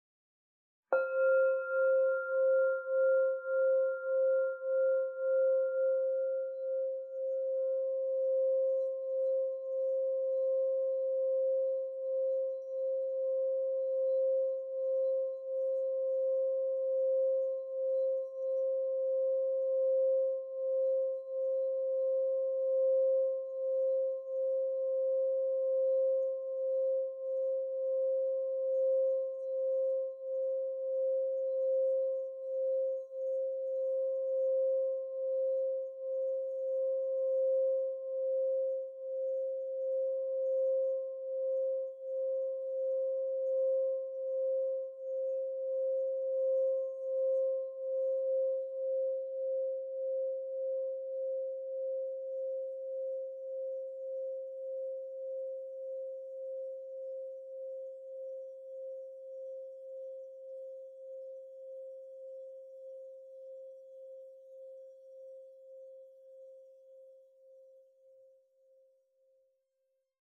Meinl Sonic Energy 4,5" Essence Solfeggio Crystal Singing Bowl Mi 528 Hz, Sunrise Blue (ESOLCSB528)